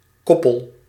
Ääntäminen
US : IPA : /tɔɹk/ RP : IPA : /tɔːk/